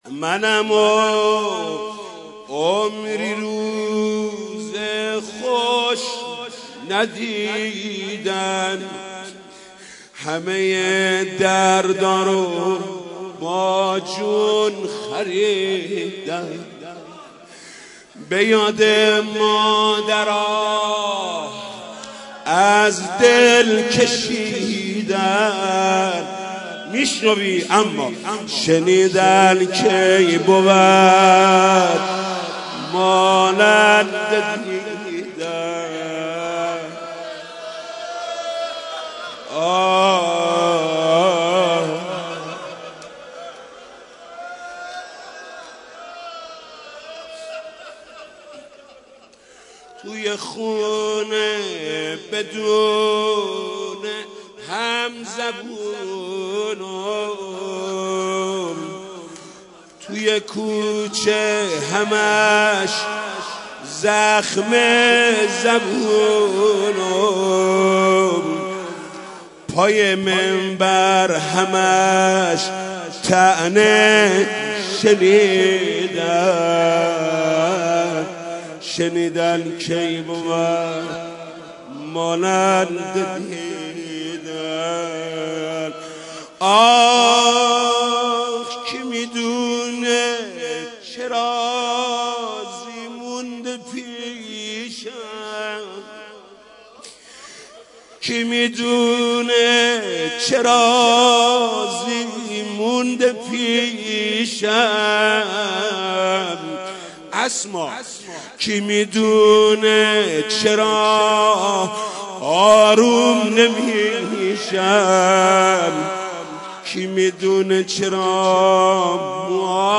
مداحی و نوحه
روضه خوانی فاطمیه ۱۳۹۵
[روضه]